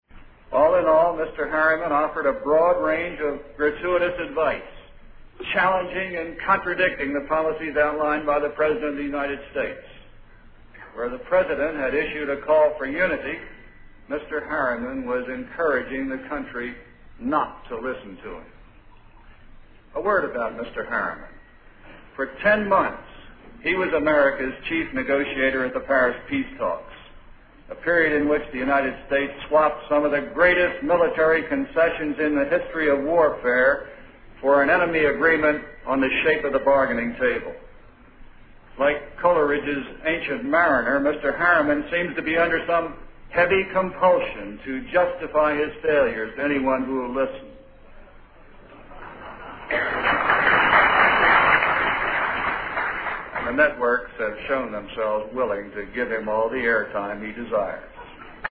经典名人英语演讲(中英对照):Television News Coverage 3